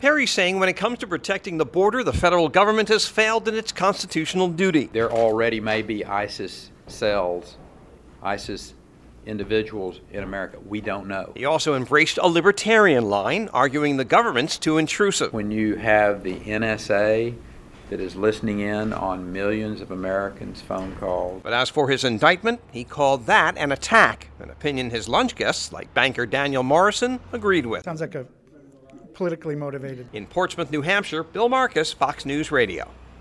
Covering Gov. Rick Perry in NH campaigning on the day he was arraigned on two felony counts of abuse of power